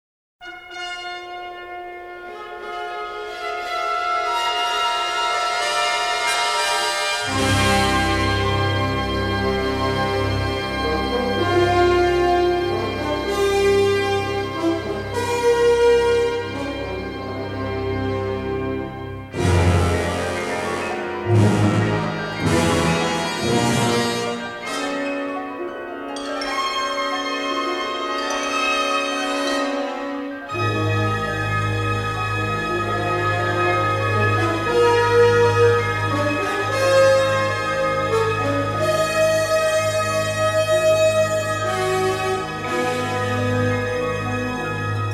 and he responded with a gorgeous, symphonic score.
stereo LP configurations